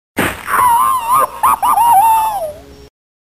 Звук боли: Том содрогается от судорог